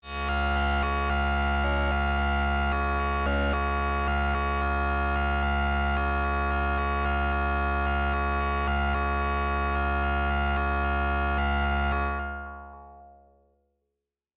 描述：通过Modular Sample从模拟合成器采样的单音。